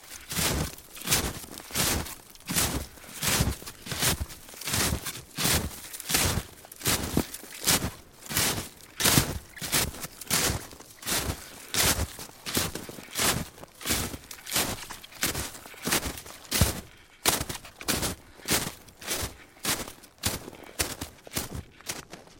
冬天 " 脚步声 雪鞋 老木头2 半深到密集的雪地 中速在一个地方
描述：脚步声雪鞋老wood2 semideep包装雪中速在一个spot.flac
Tag: 脚步 雪鞋 wood2